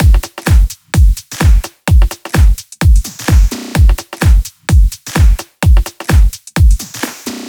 VFH3 128BPM Resistance Kit 1.wav